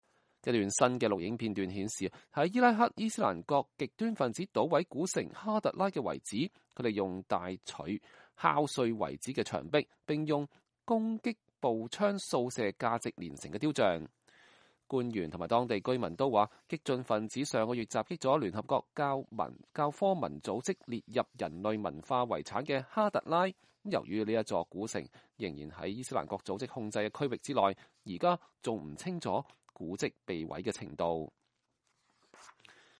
一段新的錄影片段顯示，在伊拉克的伊斯蘭國極端份子搗毀了古城哈特拉(Hatra)的遺址，他們用大錘敲碎遺址的牆壁，並使用攻擊步槍掃射價值連城的雕像。
美聯社報導，一名說阿拉伯語帶有明顯海灣地區口音的激進份子在錄影片段中講話。